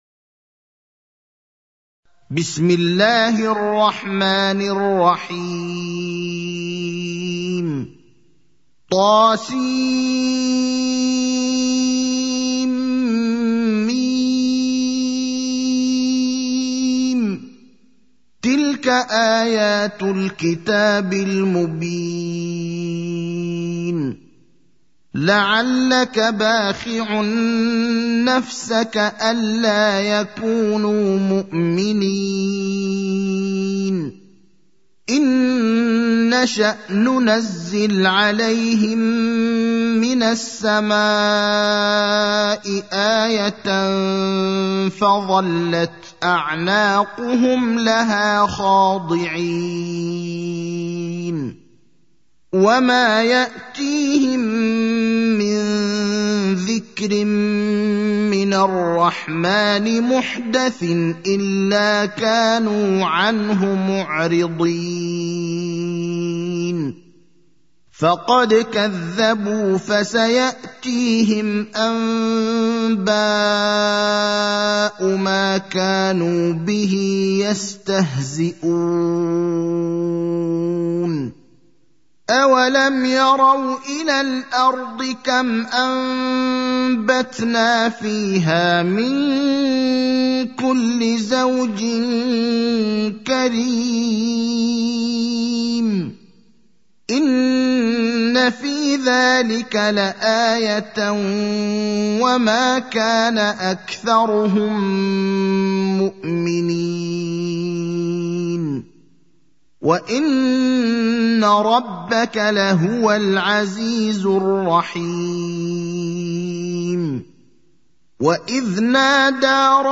المكان: المسجد النبوي الشيخ: فضيلة الشيخ إبراهيم الأخضر فضيلة الشيخ إبراهيم الأخضر الشعراء (26)